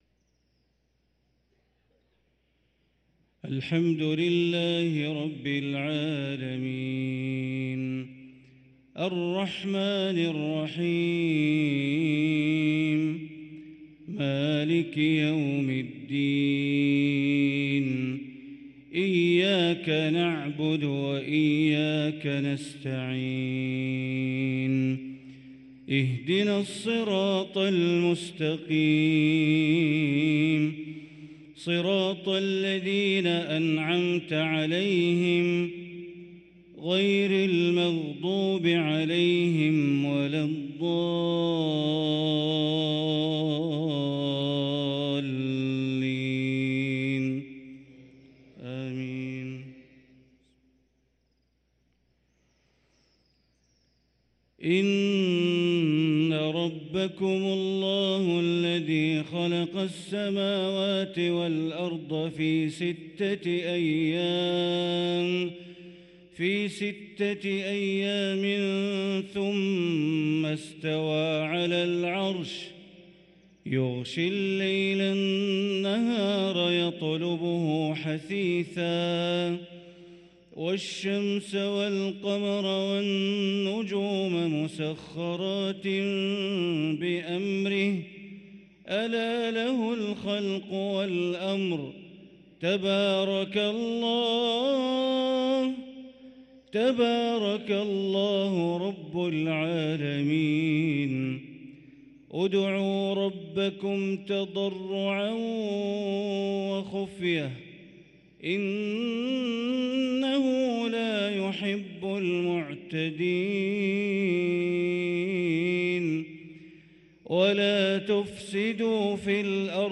صلاة المغرب للقارئ بندر بليلة 16 شعبان 1444 هـ
تِلَاوَات الْحَرَمَيْن .